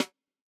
Snare Zion 1.wav